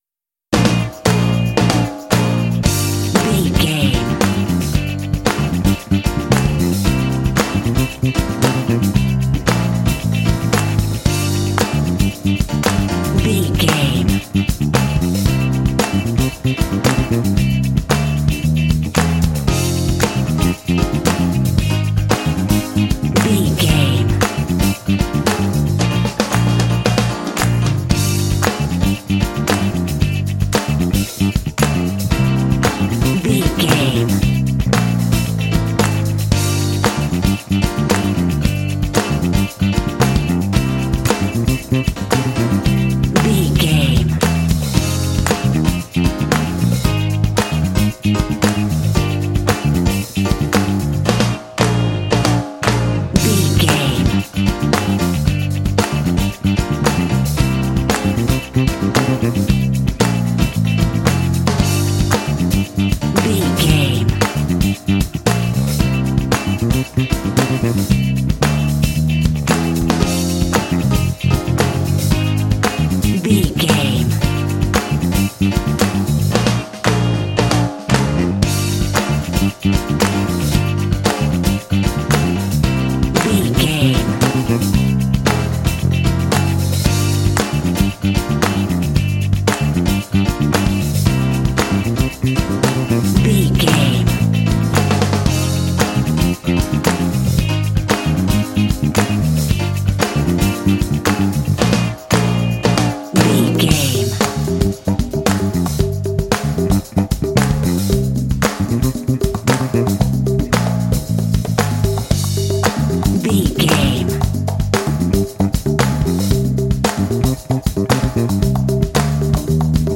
Aeolian/Minor
funky
groovy
bright
lively
energetic
bass guitar
electric guitar
piano
drums
percussion
Funk
jazz